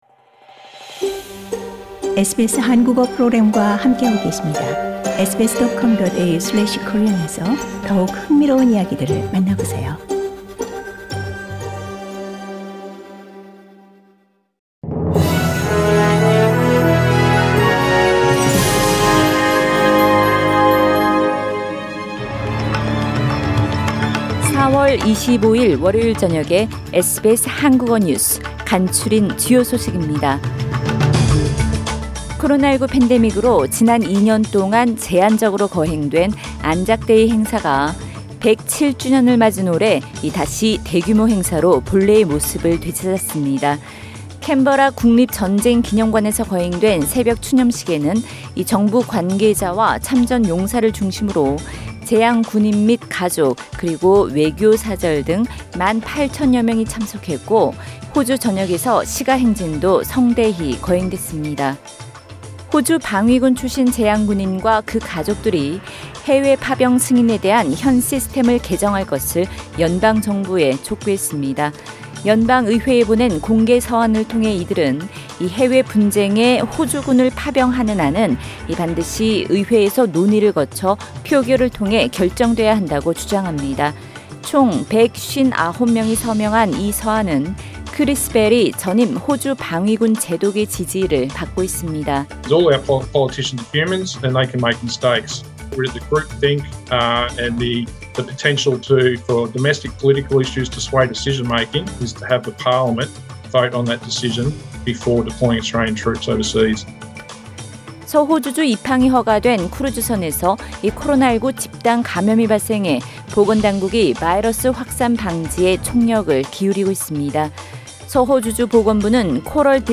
SBS 한국어 저녁 뉴스: 2022년 4월 25일 월요일